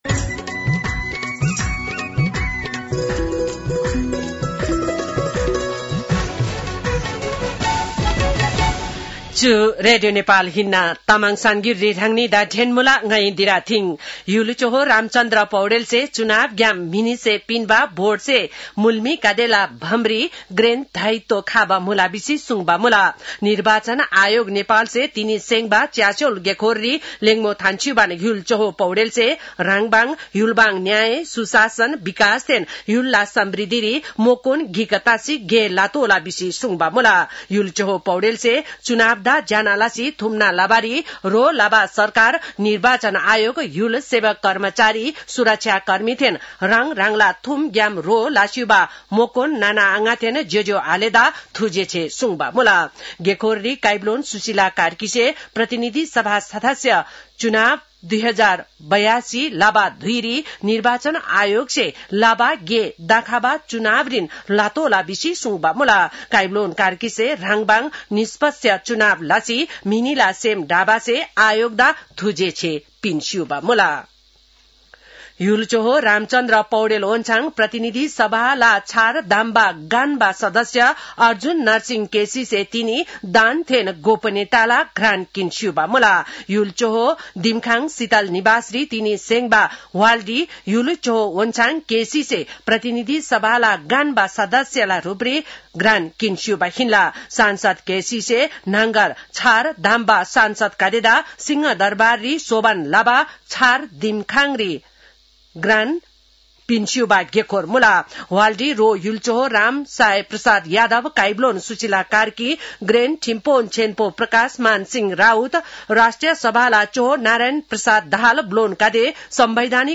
तामाङ भाषाको समाचार : ११ चैत , २०८२